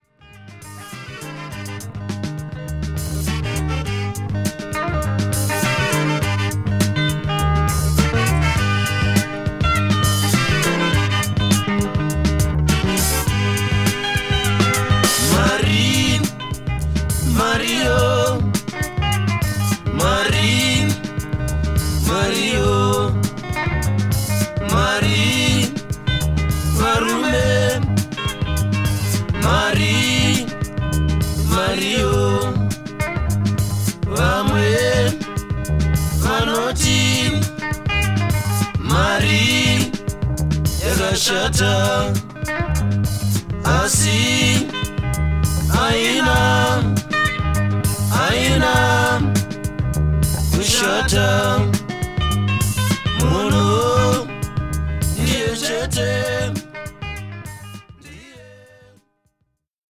ショナ族の伝統音楽にリンガラやソウル、ロックの要素をミックスしたモダン・アフロ・サウンドの傑作です。